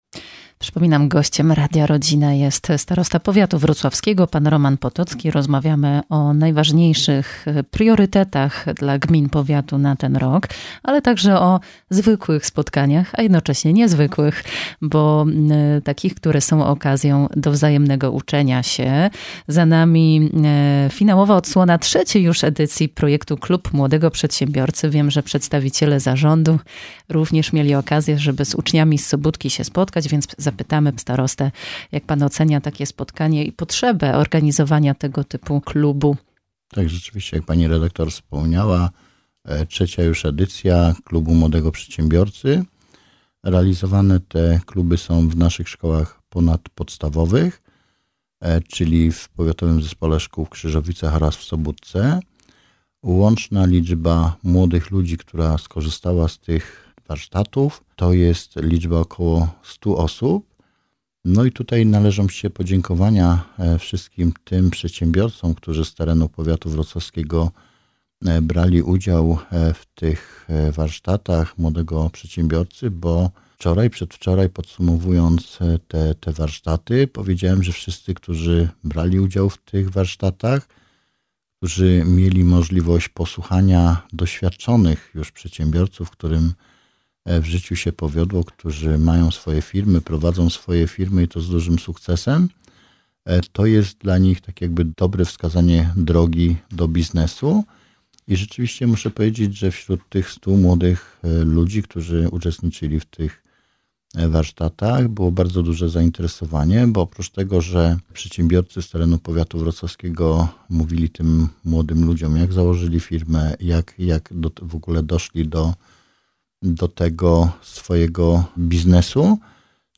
Naszym gościem jest Starosta Powiatu Wrocławskiego – Roman Potocki, z którym rozmawiamy o bieżących inwestycjach, działaniach edukacyjnych i profilaktyce w ramach polityki zdrowotnej.